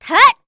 Glenwys-Vox_Attack2_kr.wav